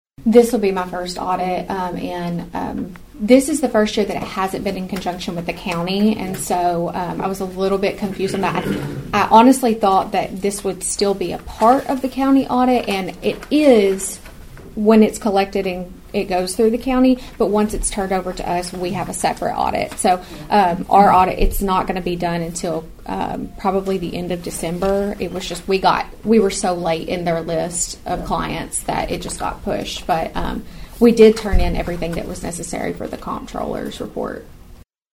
During this week’s monthly County Commission meeting